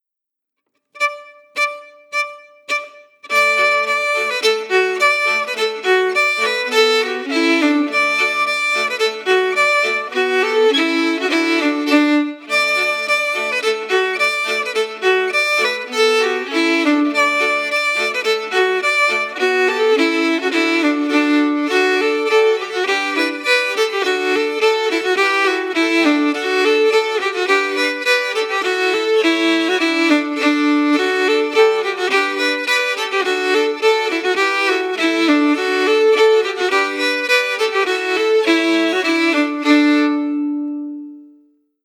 Key: D
Form: Polka
Region: Ireland